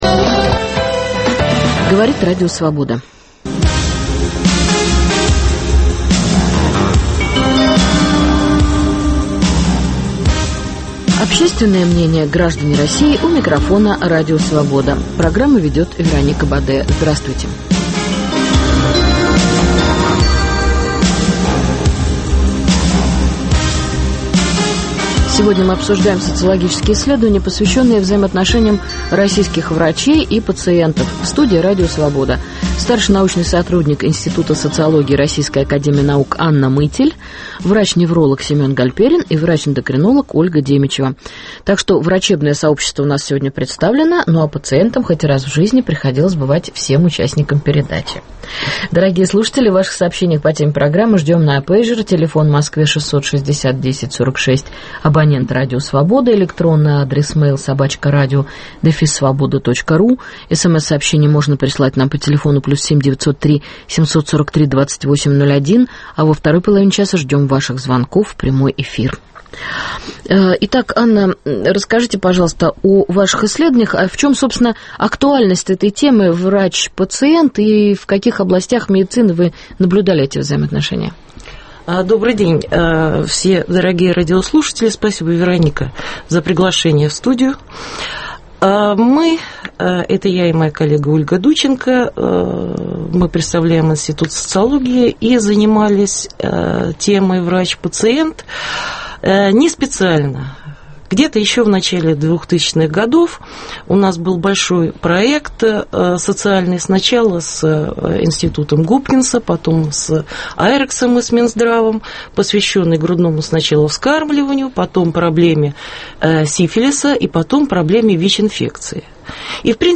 Обсуждаем социологические исследования, посвященные взаимоотношениям врачей и пациентов. В студии